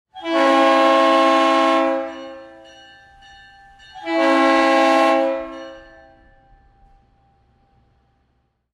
Современный гудок поезда